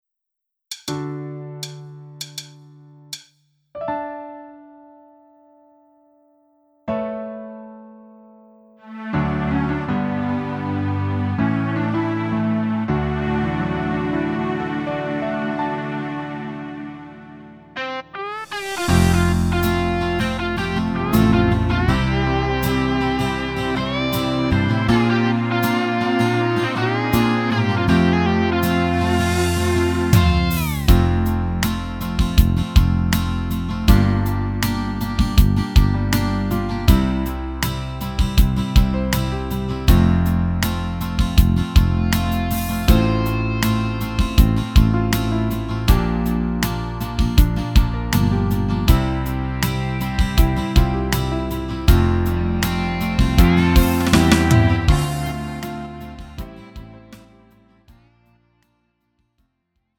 음정 -1키 3:50
장르 가요 구분 Lite MR